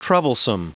Prononciation du mot troublesome en anglais (fichier audio)
Prononciation du mot : troublesome